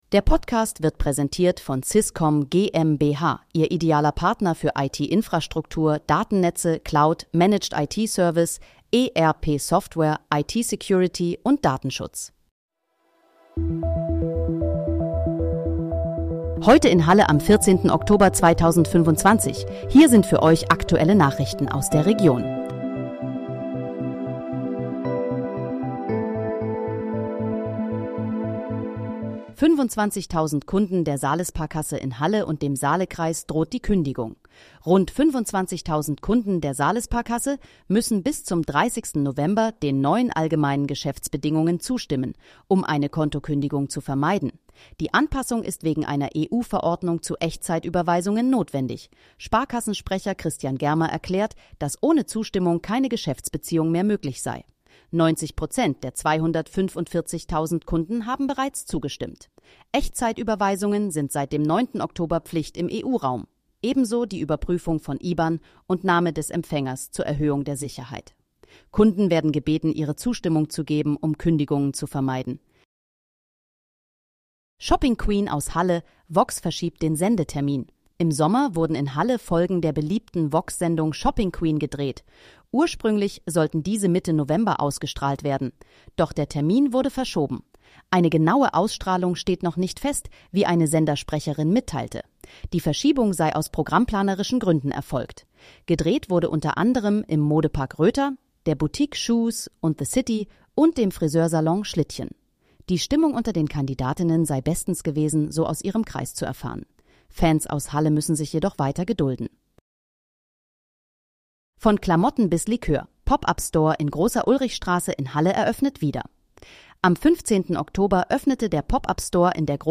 Heute in, Halle: Aktuelle Nachrichten vom 14.10.2025, erstellt mit KI-Unterstützung
Nachrichten